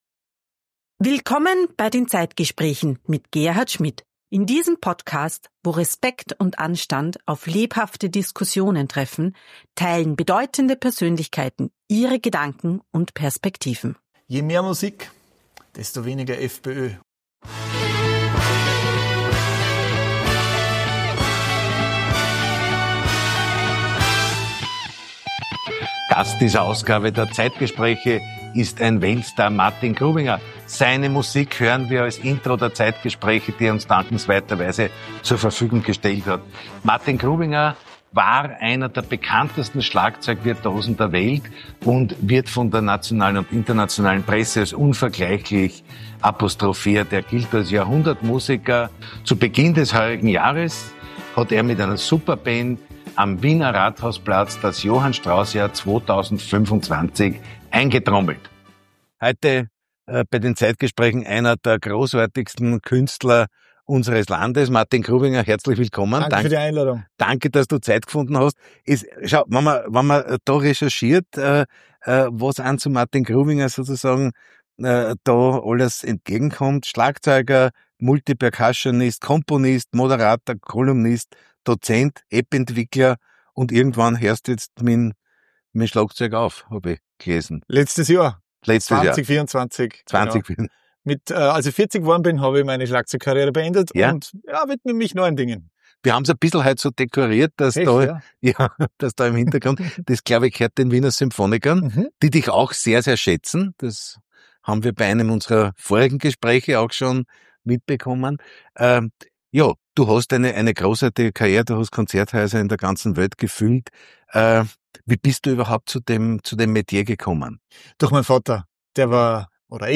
In dieser Episode der Zeitgespräche ist der weltbekannte Schlagzeuger und Musiker Martin Grubinger zu Gast.